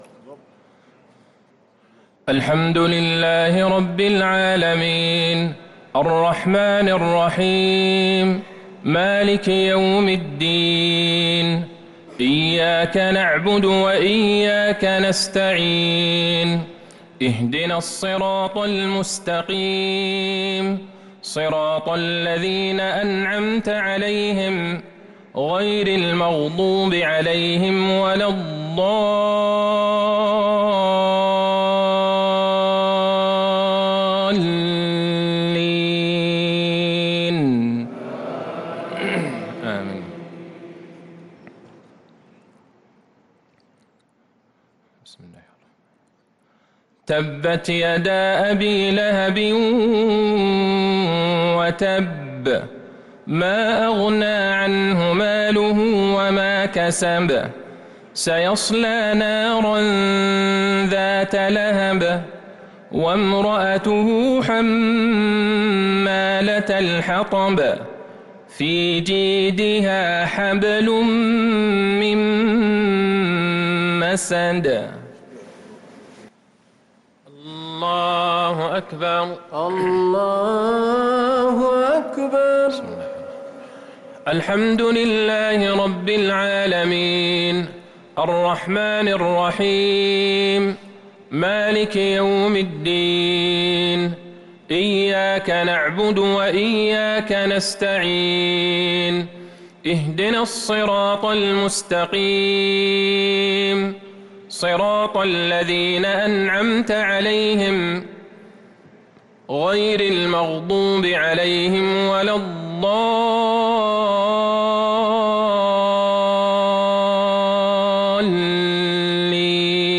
صلاة المغرب للقارئ عبدالله البعيجان 15 رمضان 1443 هـ